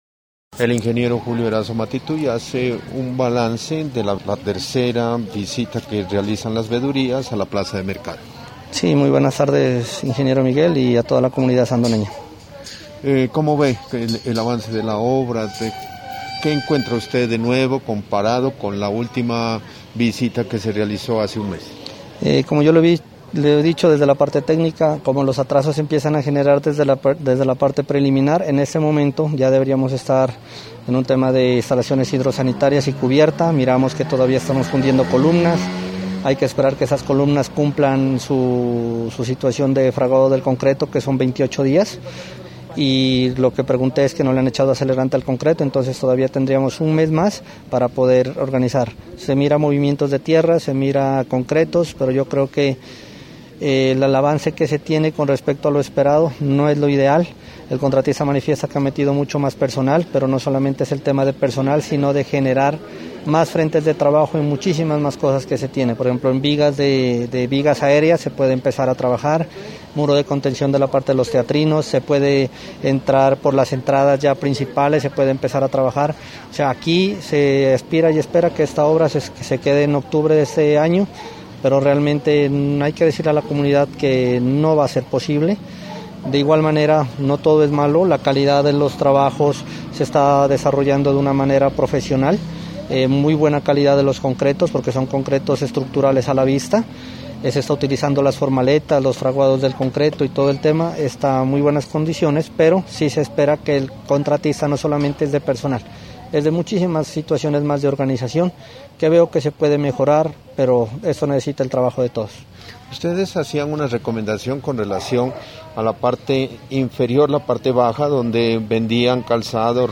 Los integrantes de las veedurías ciudadanas del proyecto de construcción de la plaza de mercado de Sandoná y dos funcionarias de la Administración Municipal visitaron la obra este martes en la tarde.